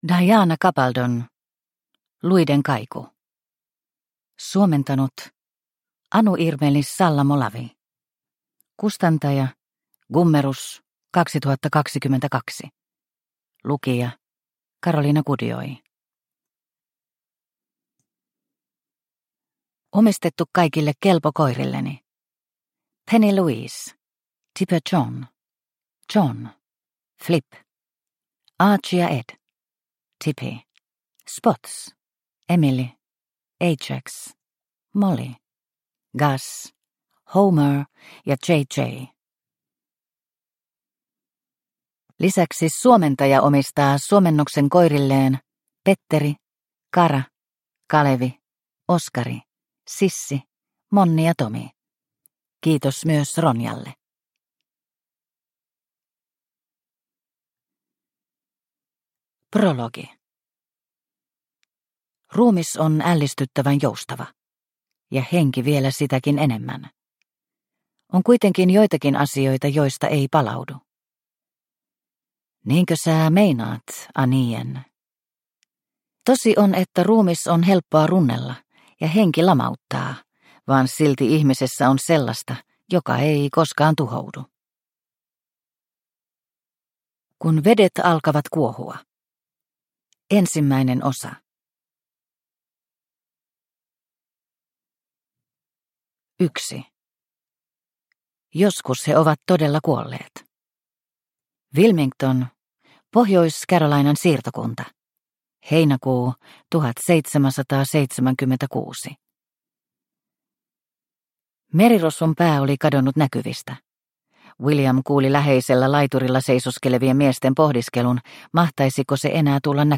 Luiden kaiku – Ljudbok – Laddas ner